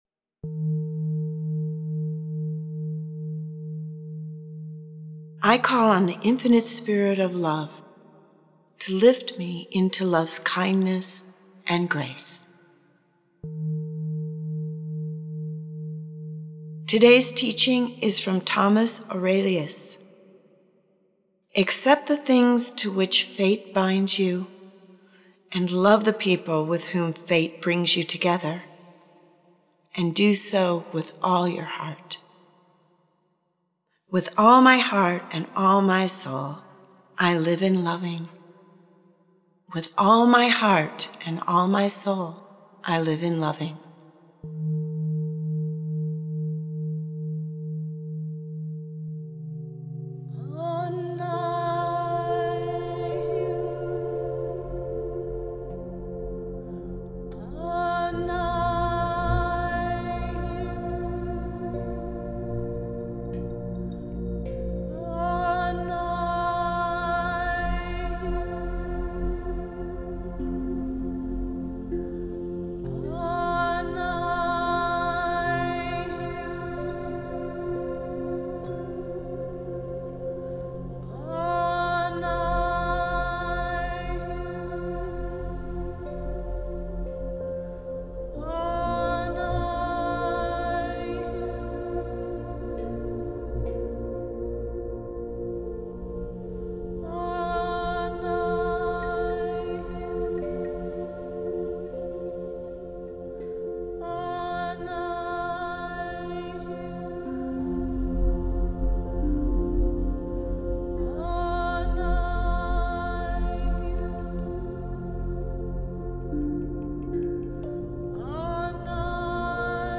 🎧 This Week’s Sacred Offering This week, we’re sharing a Meditation to support you in deepening your awareness and opening your heart to love.